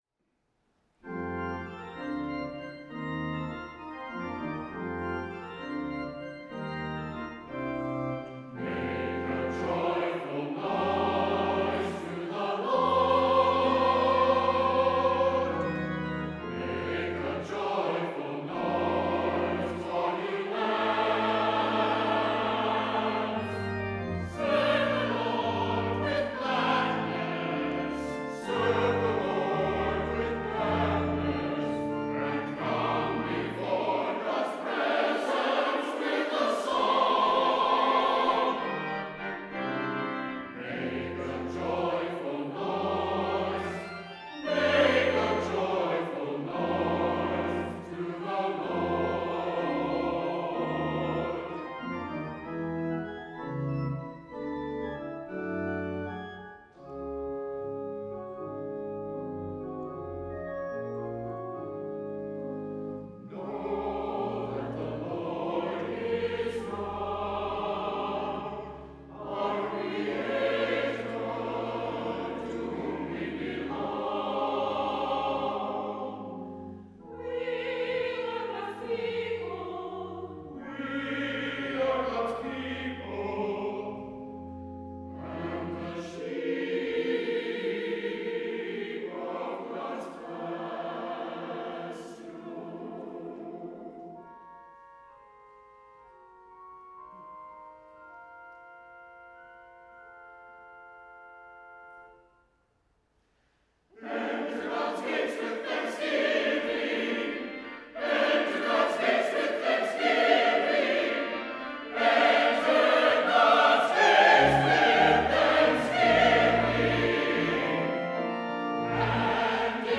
Chancel Choir
08_Anthem-MakeAJoyfulNoise-Hobby.mp3